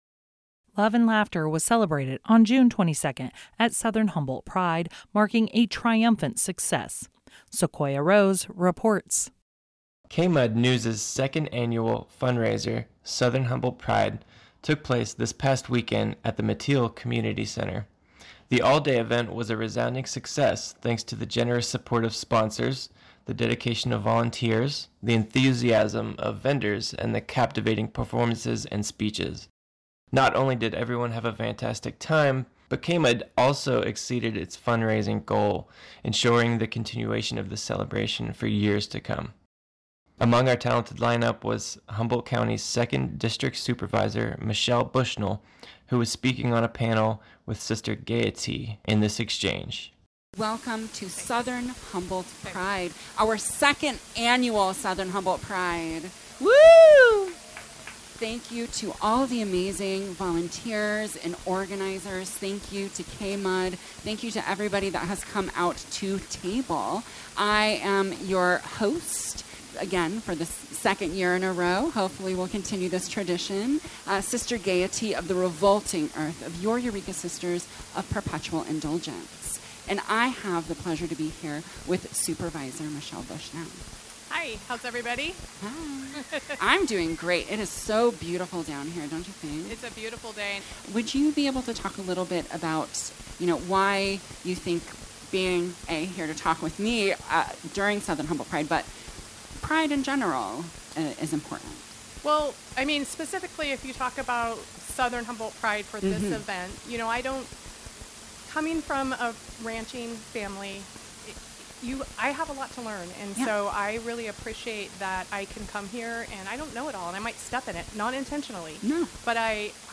Conservative Icon's Heart-Shifting Speech: Supervisor Bushnell at Southern Humboldt PRIDE